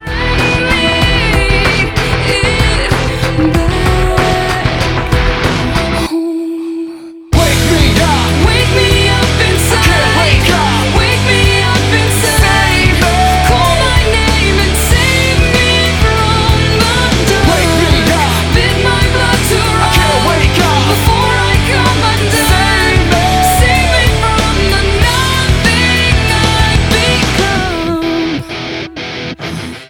• Rock